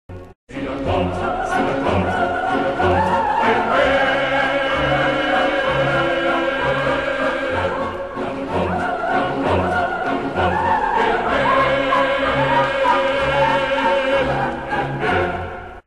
Konzertwalzer
für gemischten Chor und Orchester
Besetzung: gemischter Chor, Klavier (ggf. 4-händig)